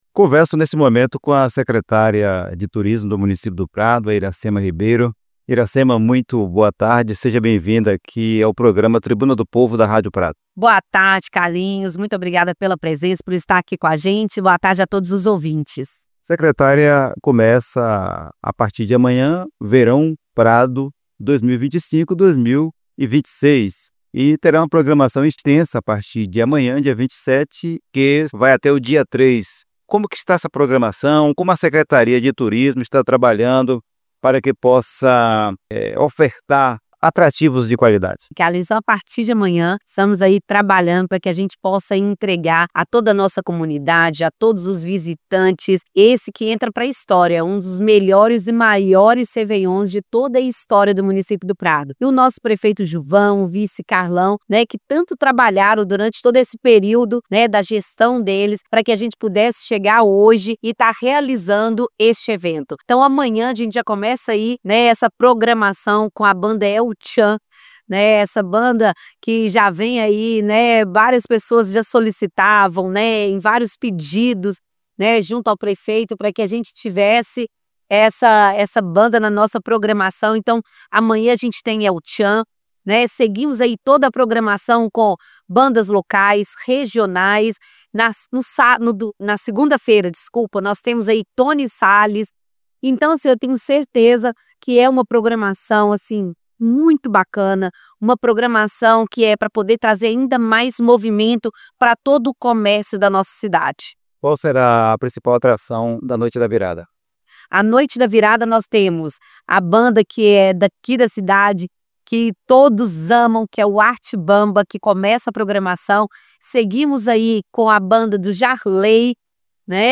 Em entrevista à Rádio Prado FM, a secretária municipal de Turismo, Iracema Ribeiro, destacou que o evento está entre os maiores já realizados no município, fruto do trabalho da gestão do prefeito Juvão e do vice-prefeito Carlão.
Entrevista: